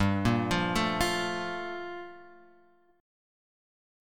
Gm9 chord {3 1 3 2 x 1} chord